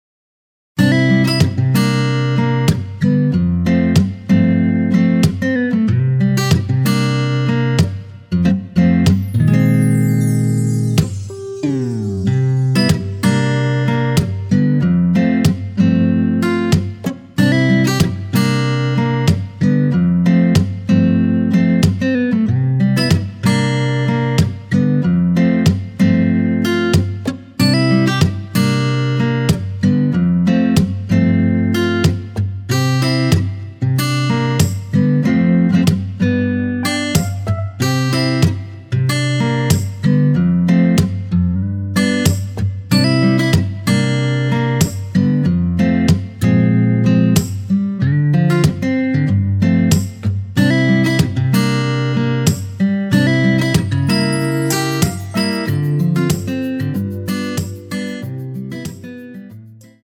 (-1)내린 MR 입니다.(미리듣기 참조)
앞부분30초, 뒷부분30초씩 편집해서 올려 드리고 있습니다.
중간에 음이 끈어지고 다시 나오는 이유는